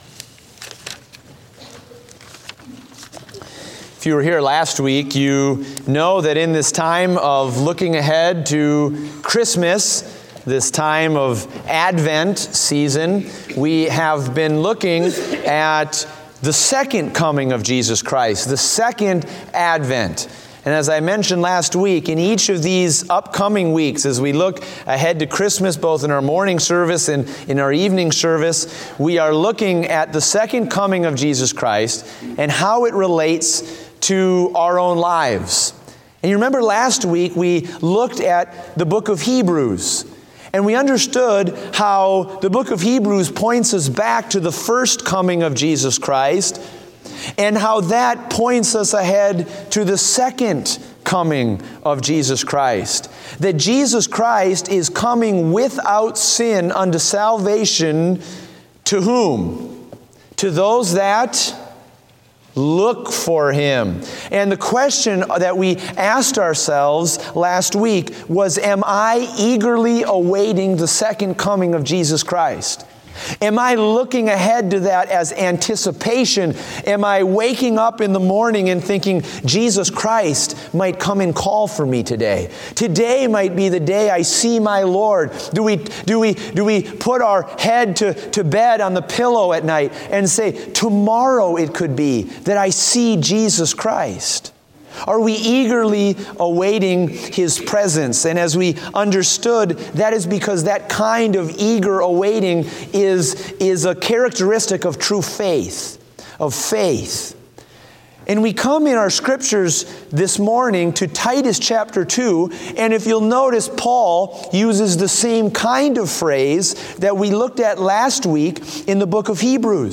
Date: December 11, 2016 (Morning Service)